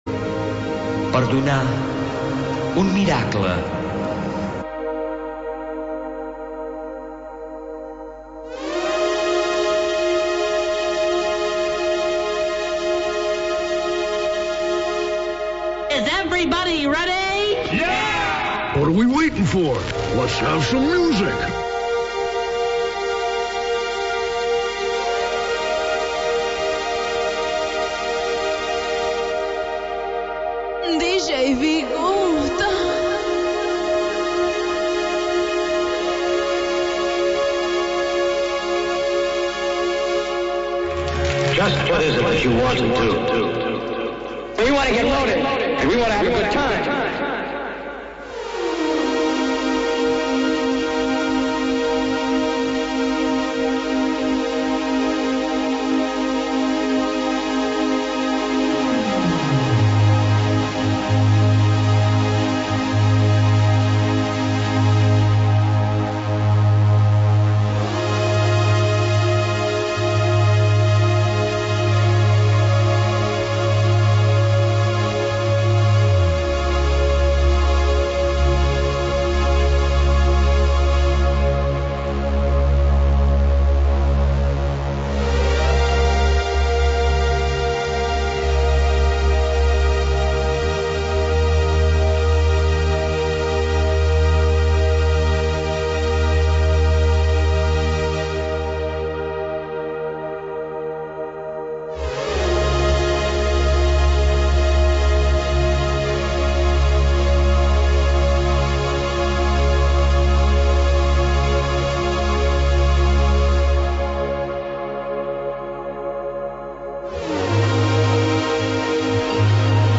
Selecció musical independent